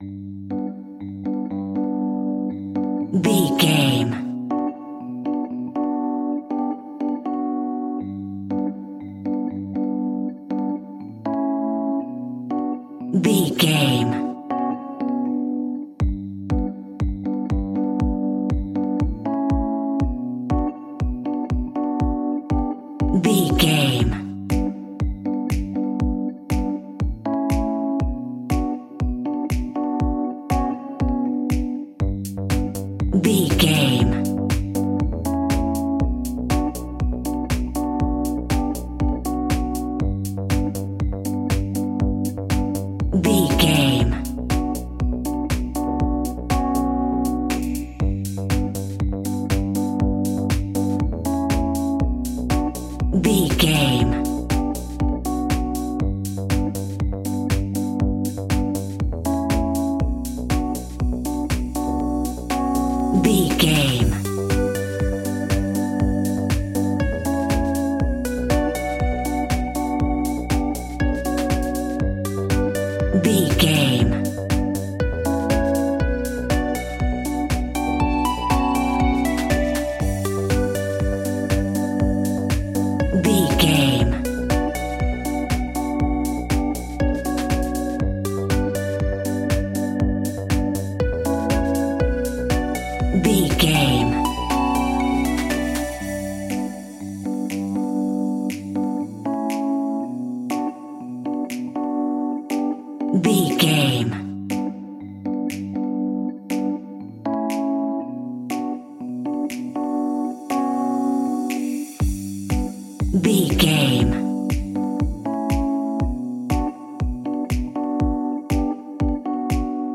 Aeolian/Minor
Slow
groovy
peaceful
tranquil
meditative
smooth
drum machine
electric guitar
synthesiser
synth leads
synth bass